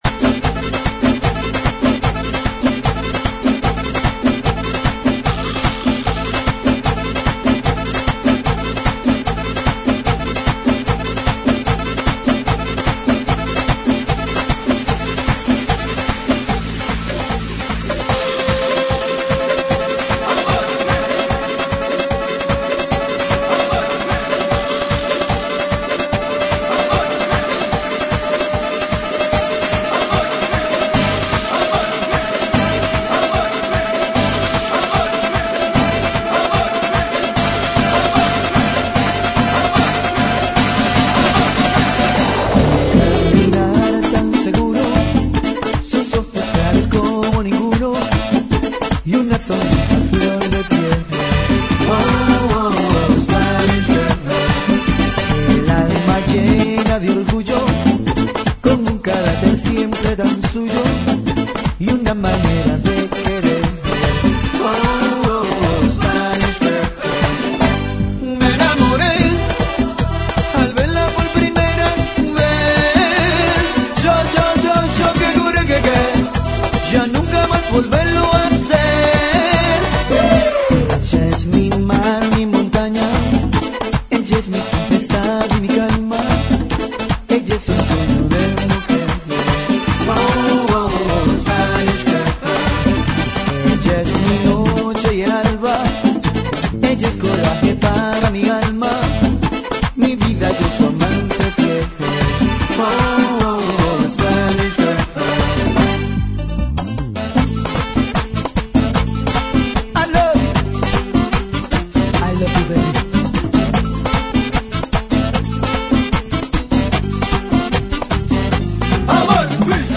GENERO: LATINO – TRIBAL